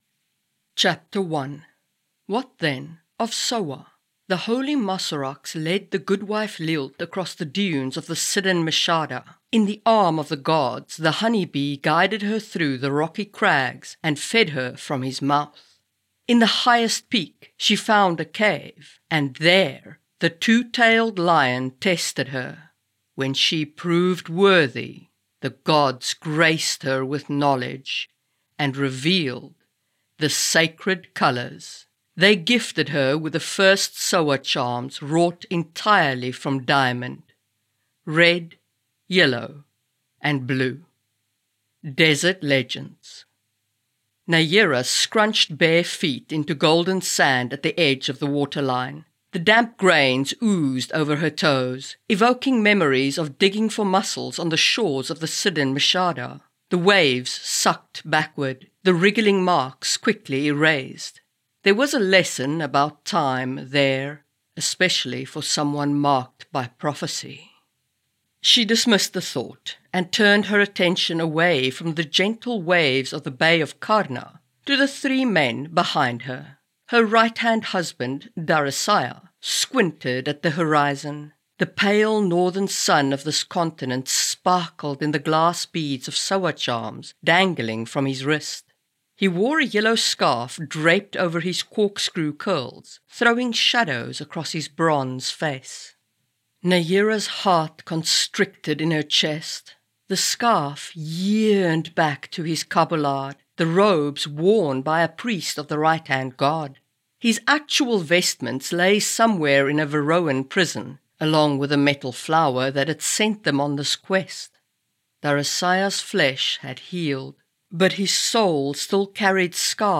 Lion Vessel (Audio Book) | Marie Mullany